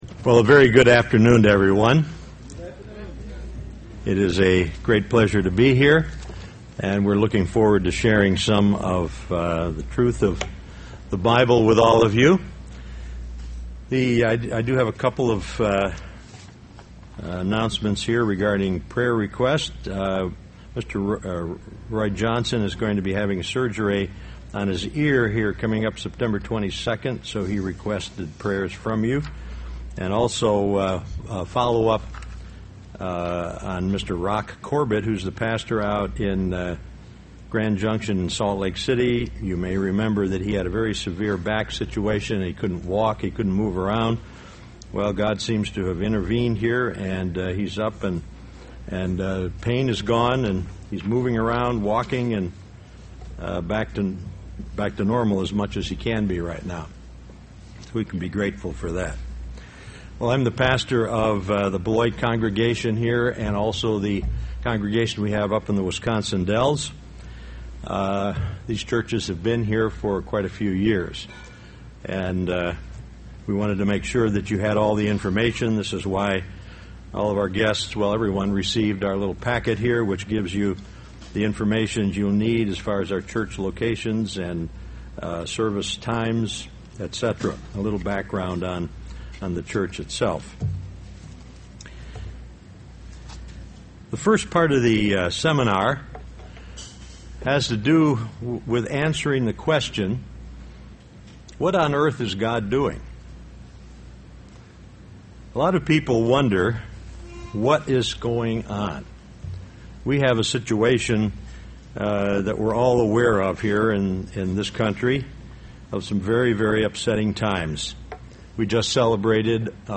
Why does God allow certain things to happen? This message was given as a Kingdom of God Bible seminar.